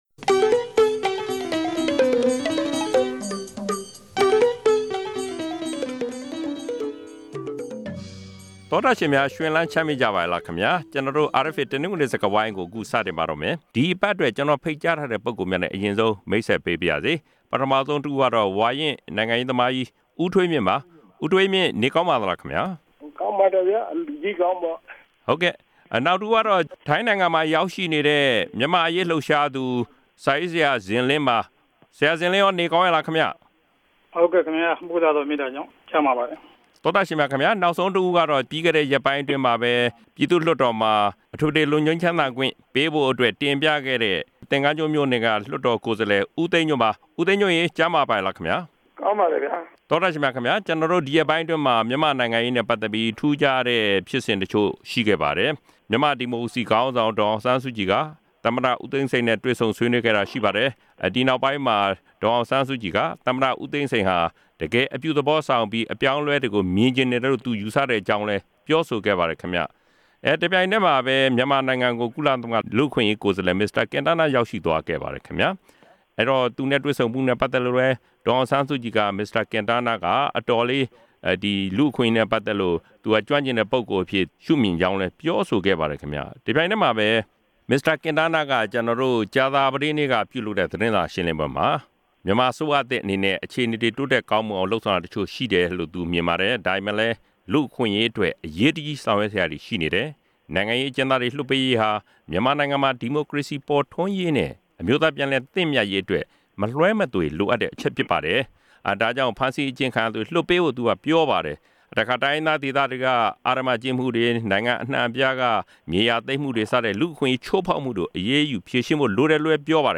ဒီစကားဝိုင်းမှာ ပါဝင်ဆွေးနွေးထားသူတွေကတော့ ဝါရင့်နိုင်ငံရေးသမားကြီး